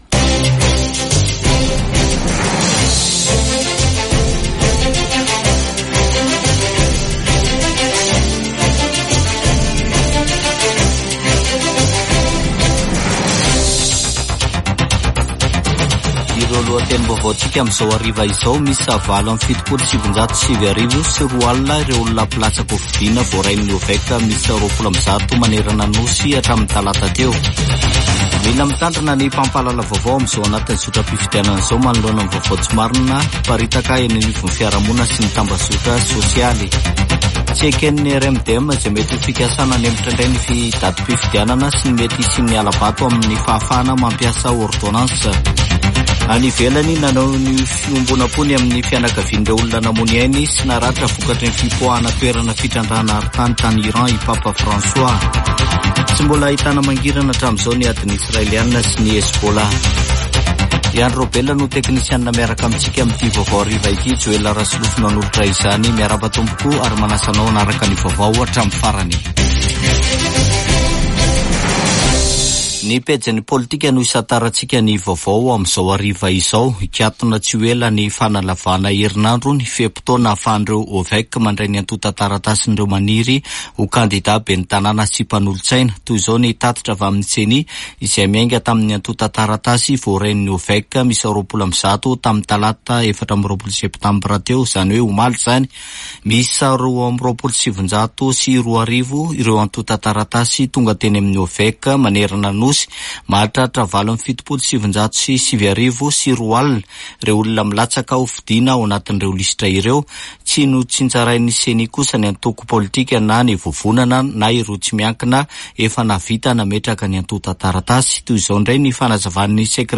[Vaovao hariva] Alarobia 25 septambra 2024